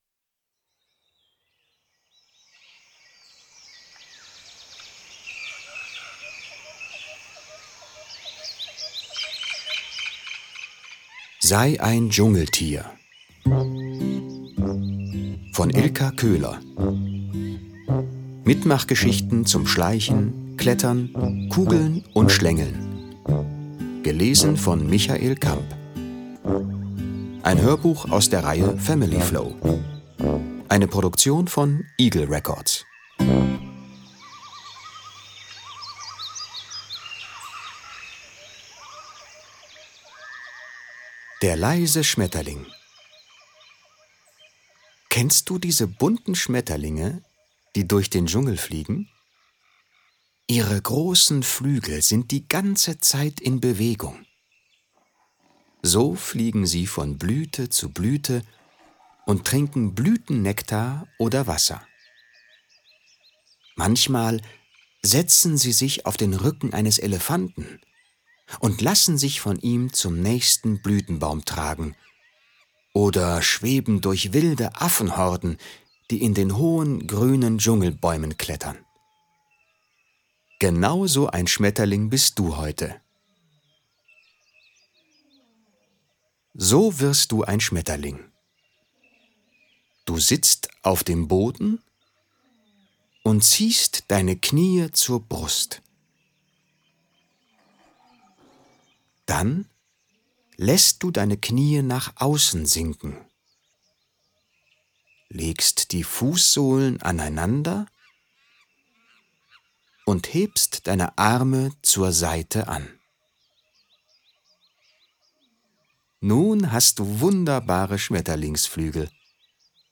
Hörbuch: FamilyFlow.